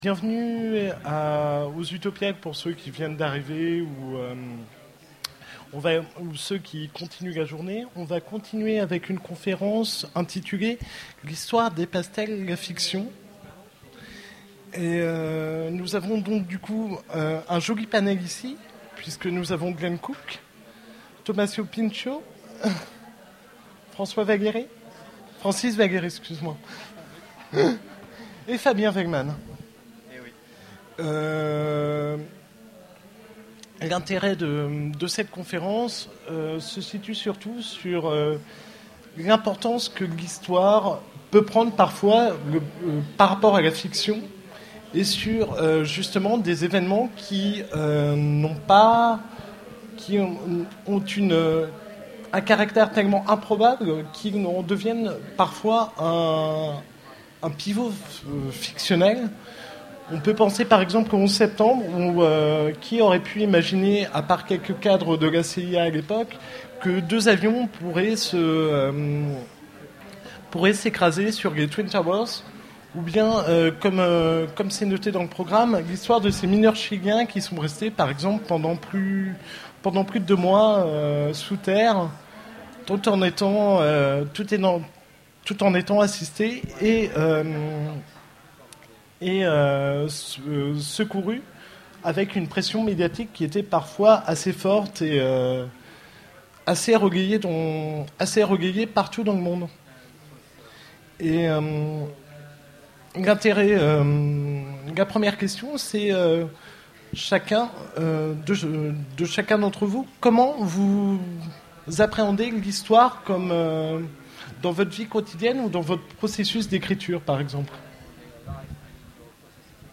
Utopiales 2011 : Conférence L'histoire dépasse-t-elle la fiction ?